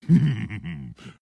Vo_tiny_tiny_happy_03.mp3